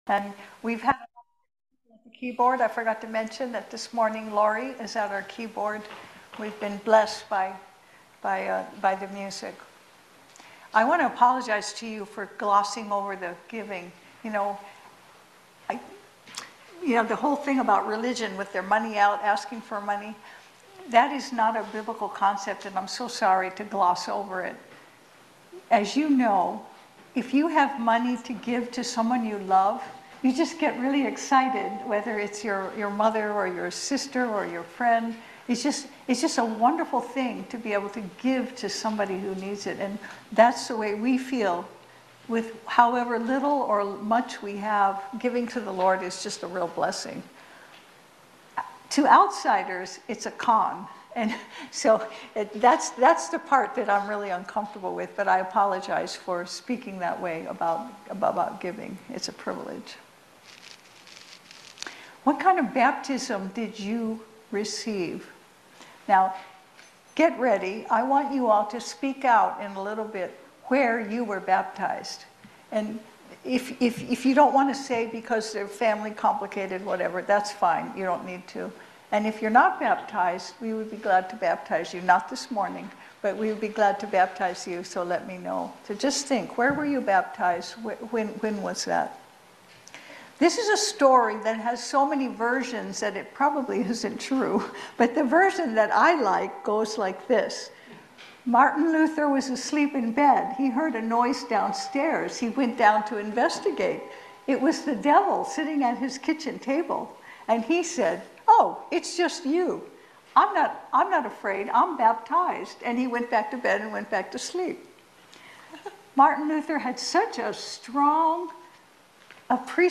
Acts 19:1-7 Service Type: Sunday Service Baptism in the name of Jesus